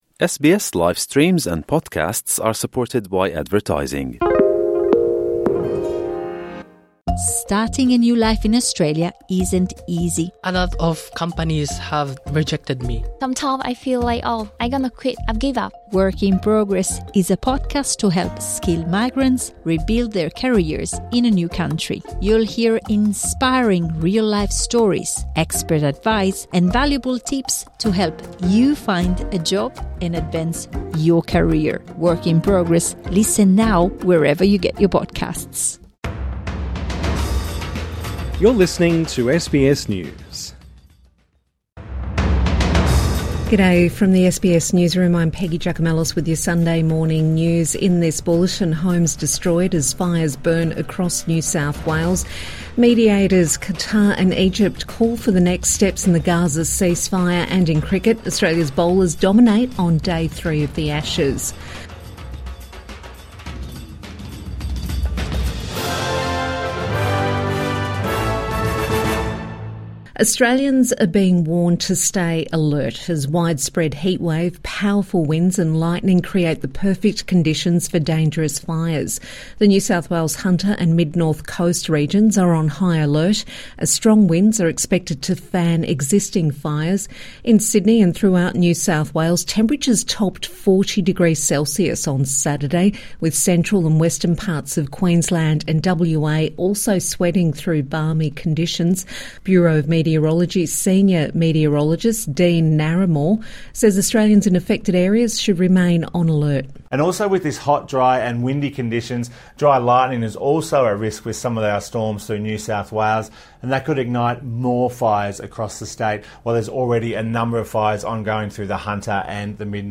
Homes destroyed as fires burn across New South Wales | Morning News Bulletin 7 December 2025